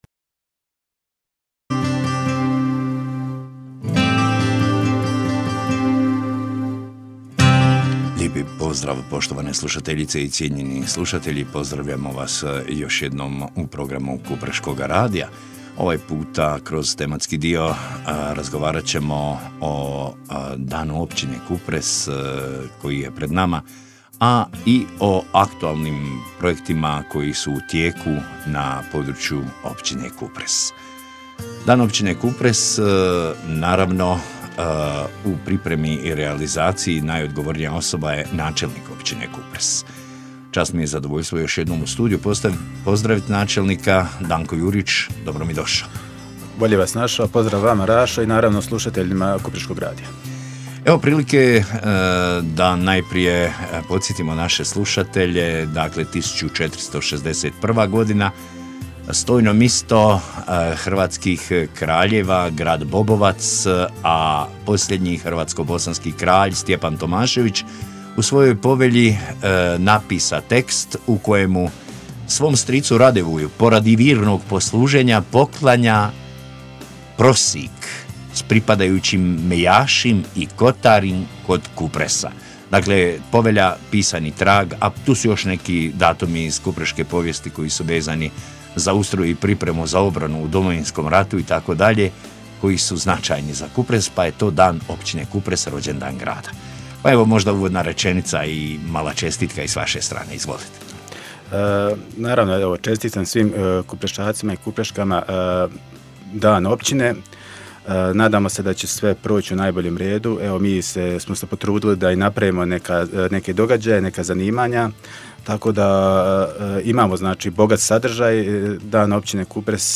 Razgovor s načelnikom Općine Kupres g. Dankom Juričem: Dan općine i aktualni programi
Povodom obilježavanja Dana općine Kupres, u našem programu ugostili smo načelnika općine gospodina Danku Juriča koji je sa slušateljima podijelio osvrt na dosadašnje aktivnosti, ali i najavio projekte koji će obilježiti predstojeće razdoblje.